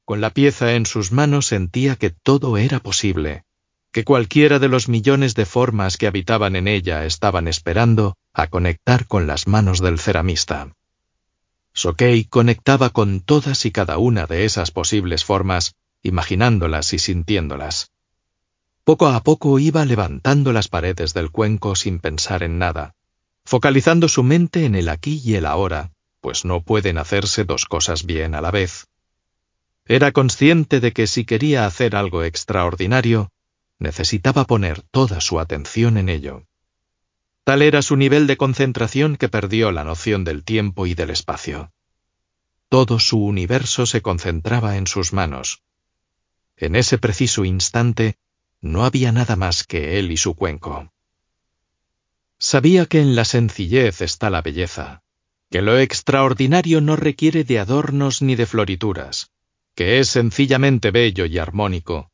audiolibro Kintsukuroi El arte de curar heridas emocionales Tomas Navarro